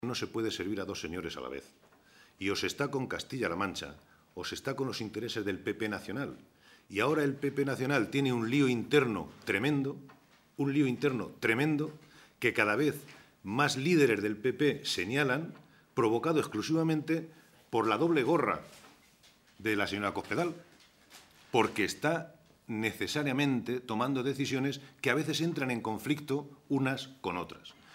Santiago Moreno, portavoz del Grupo Parlamentario Socialista
Cortes de audio de la rueda de prensa